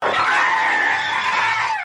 Screech Scream
Screech Scream is a free horror sound effect available for download in MP3 format.
Screech Scream.mp3